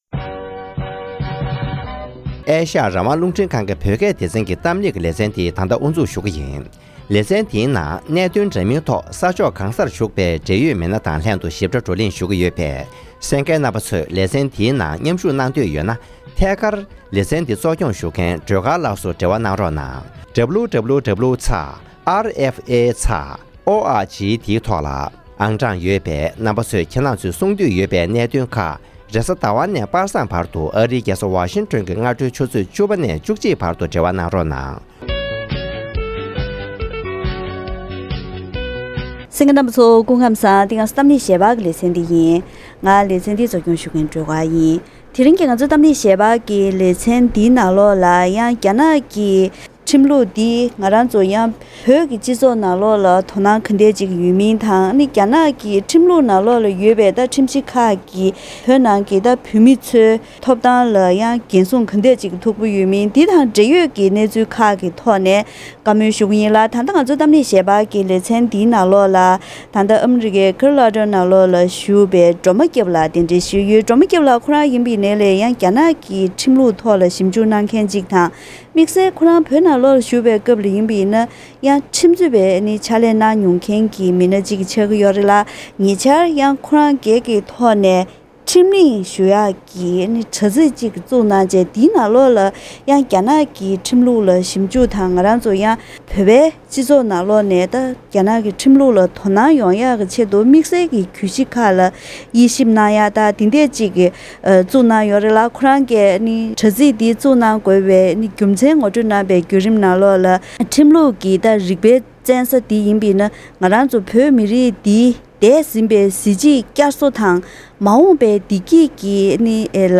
ཐེངས་འདིའི་གཏམ་གླེང་ཞལ་པར་གྱི་ལེ་ཚན་ནང་ནང་དུ།